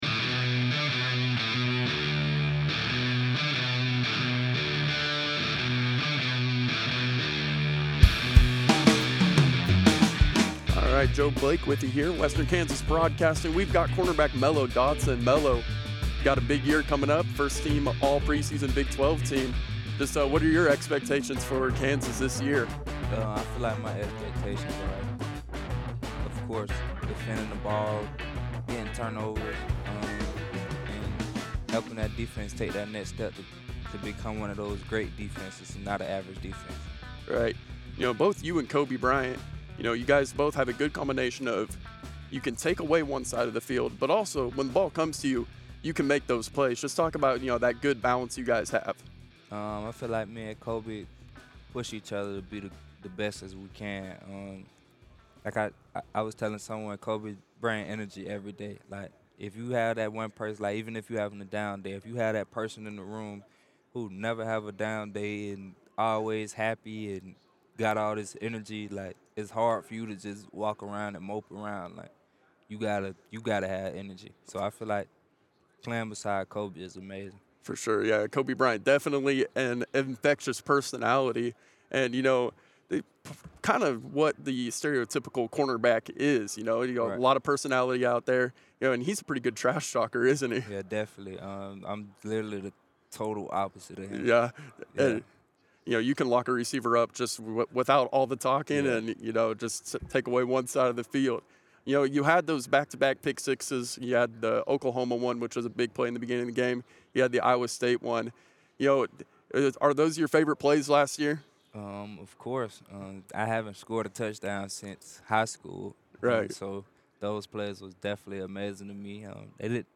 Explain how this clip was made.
Las Vegas, NV – Western Kansas News sports staff spent this week at the Big 12 Media Days covering the Kansas State Wildcats and the Kansas Jayhawks, as well as acquiring exclusive interviews with other programs and members of the media.